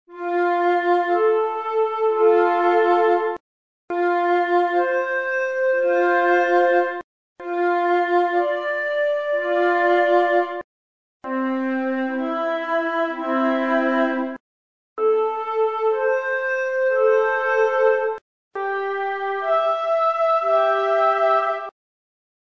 Osserviamo: Ascoltiamo la successione: ogni intervallo viene eseguito prima melodicamente , poi armonicamente . mel_arm.mp3